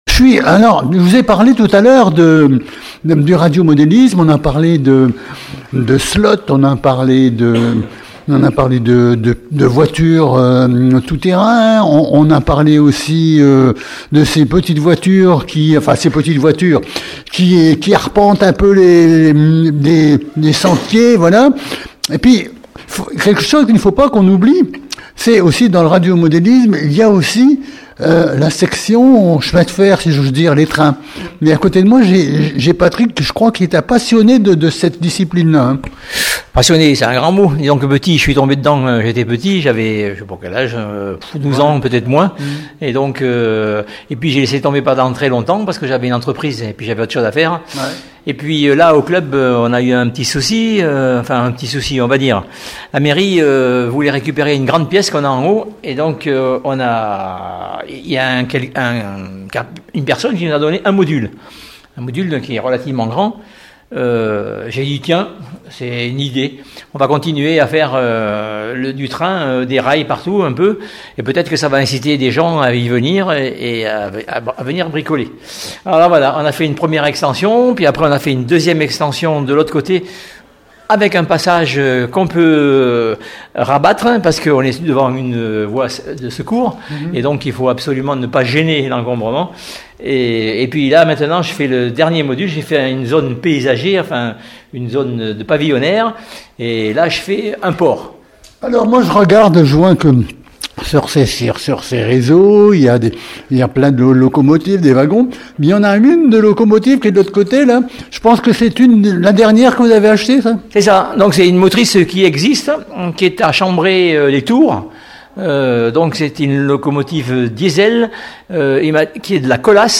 VAG MUSIC-RADIOMODELISME-INTERVIEW NO 2